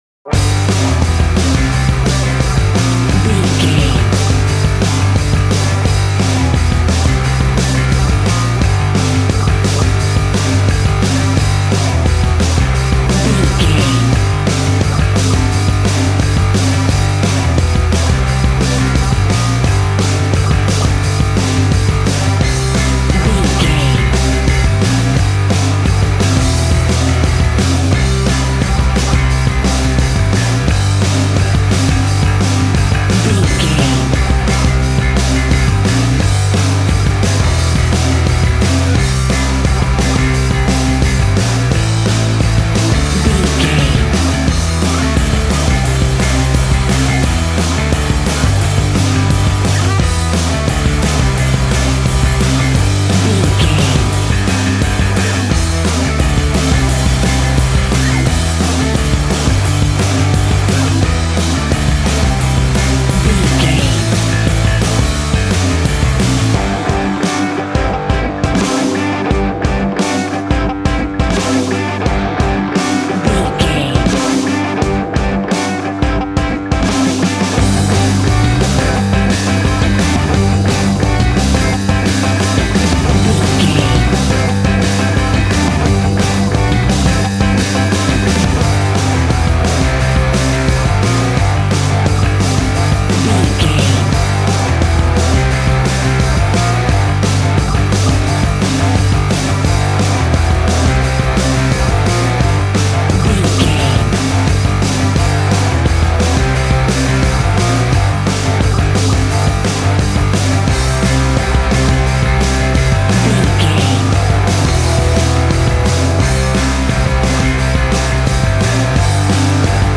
Ionian/Major
groovy
powerful
electric organ
drums
electric guitar
bass guitar